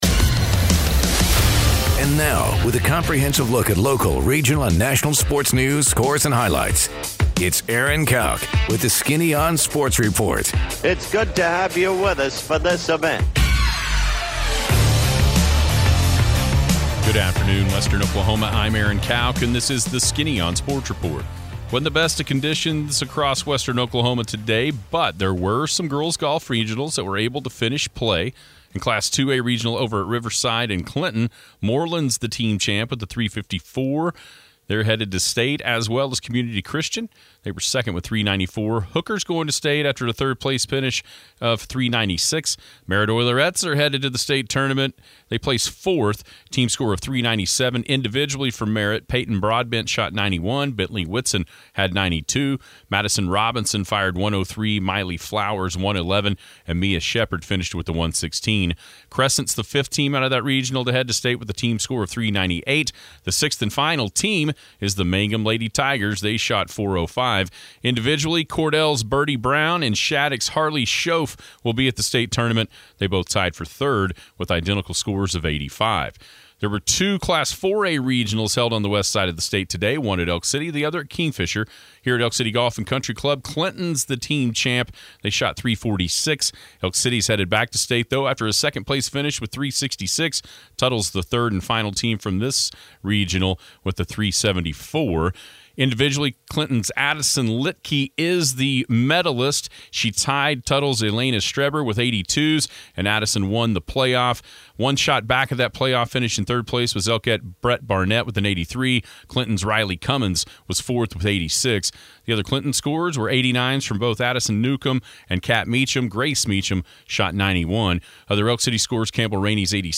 Sports Report